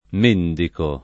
mendicare v.; mendico [